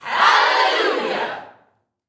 Группа людей